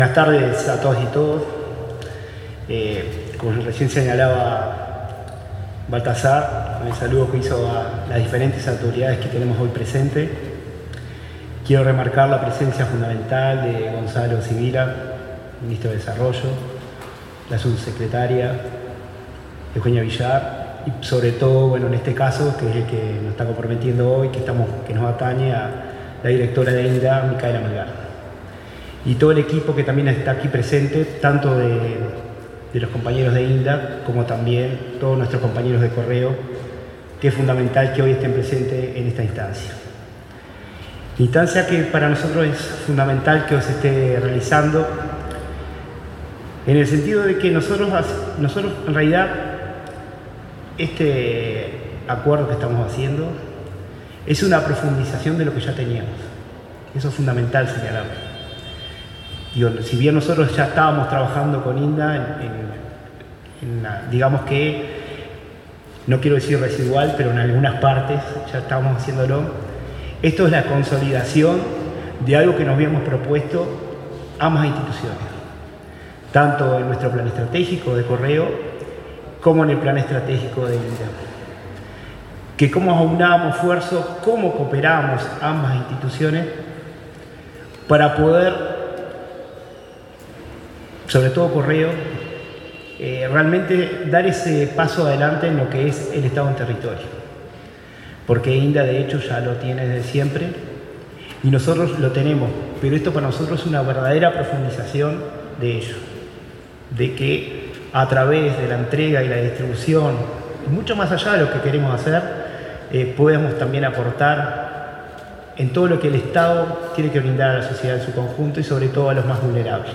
Palabras del presidente del Correo, Gabriel Bonfrisco
En la firma de un acuerdo interinstitucional estratégico entre el Correo Uruguayo y el Instituto Nacional de Alimentación (INDA) del Ministerio de